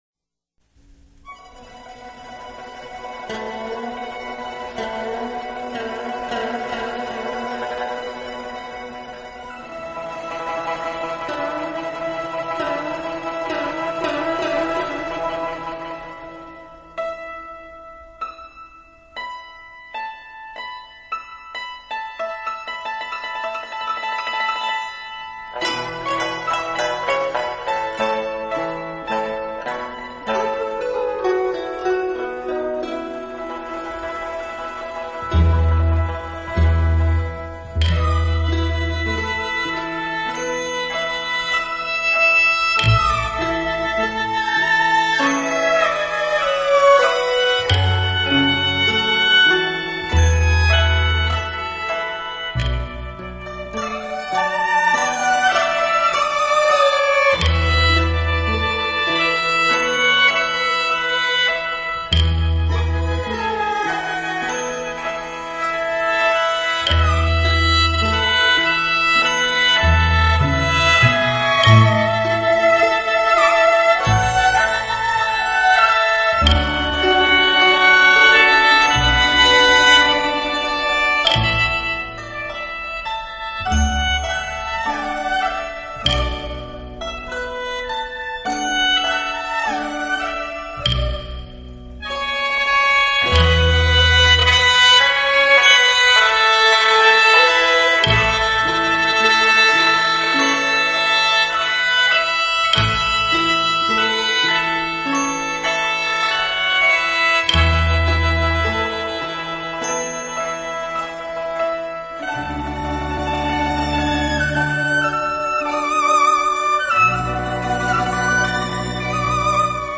以中国民族管乐乐器中的笛子、笙、唢呐、管子、塤等
笙